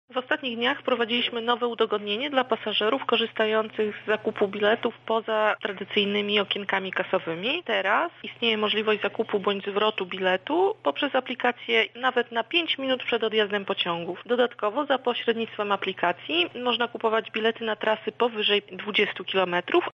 O szczegółach mówi